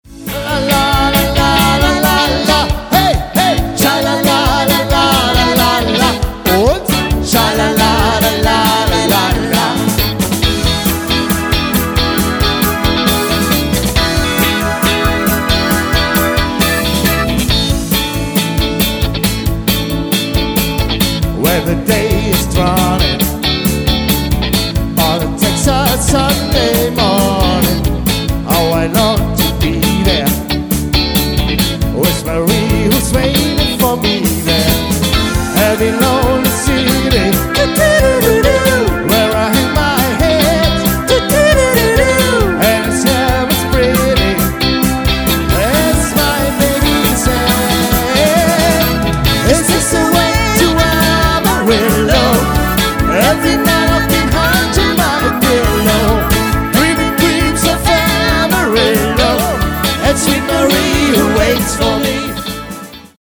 • Allround Partyband